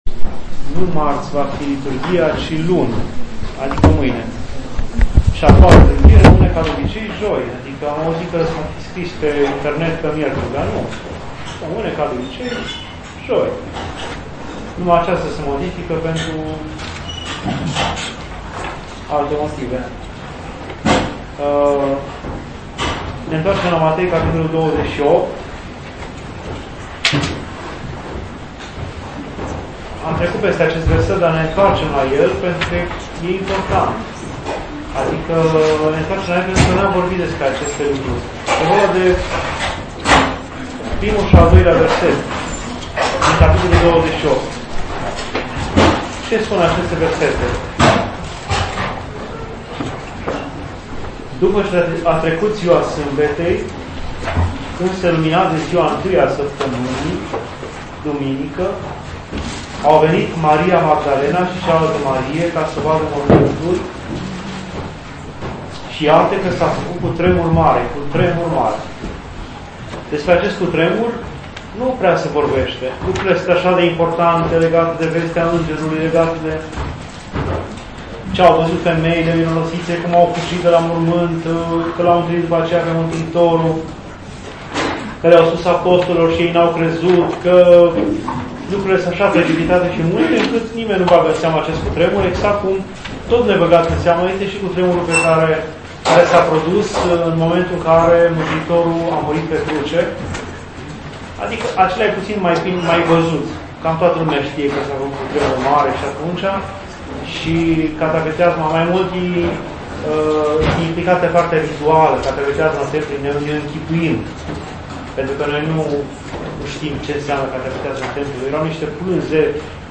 Aici puteți asculta și descărca înregistrări doar de la Bisericuța din Hașdeu